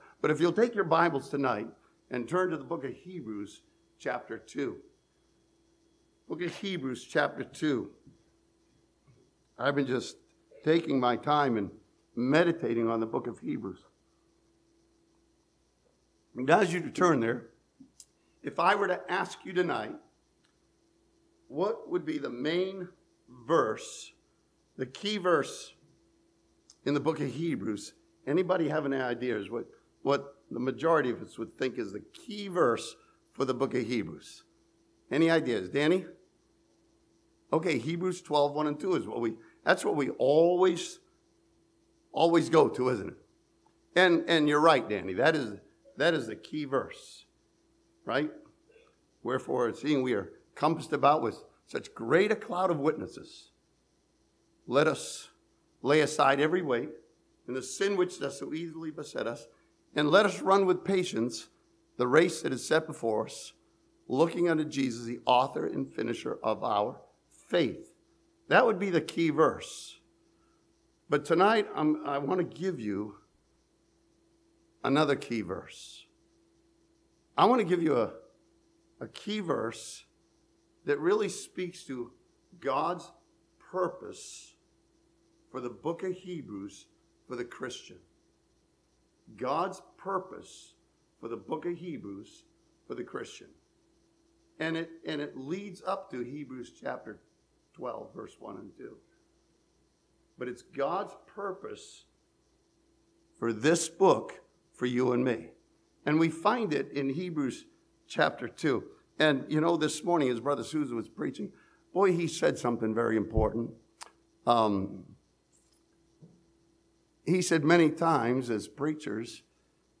This sermon from Hebrews chapter 2 challenges us to look to Jesus Christ and trust Him with our lives.